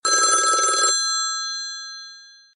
Kategorien Telefon